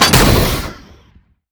JackHammer_1p_03.wav